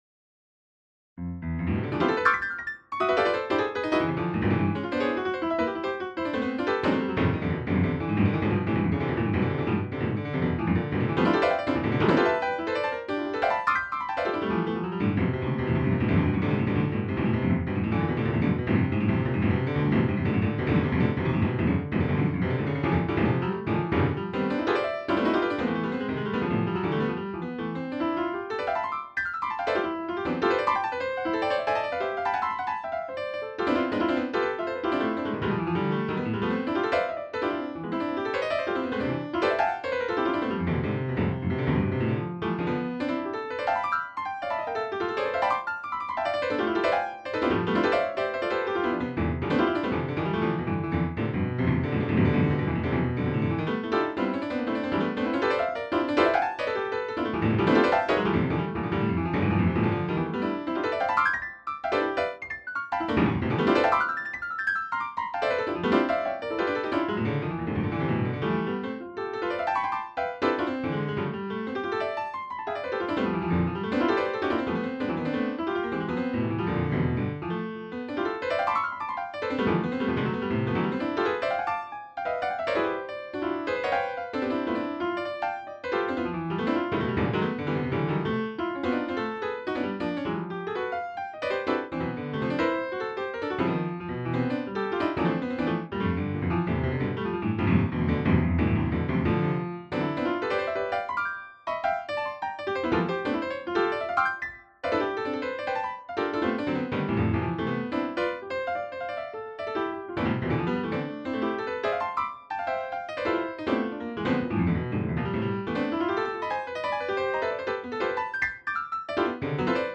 真の感覚とのハーモニーが全体を整える！
カテゴリー: 練習未発表